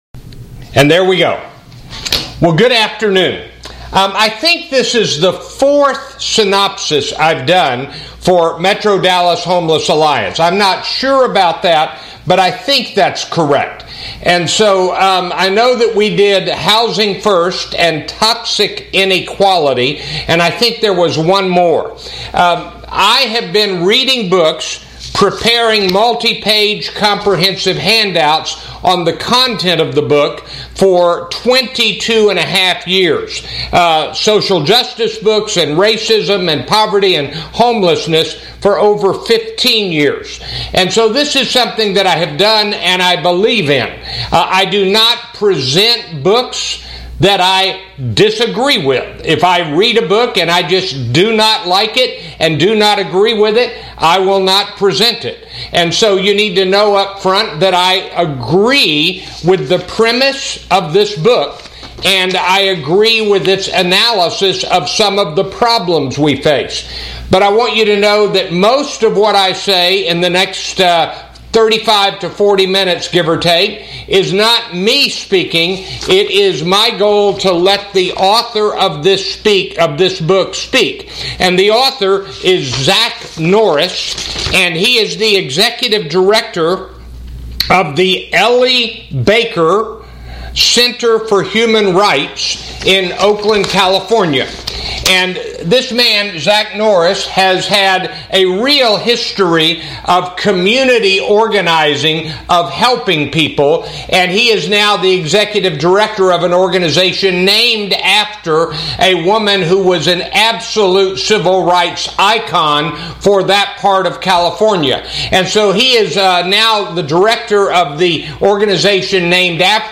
Due to the pandemic, this Hard Conversation was held virtually.